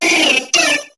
Cri de Piafabec dans Pokémon HOME.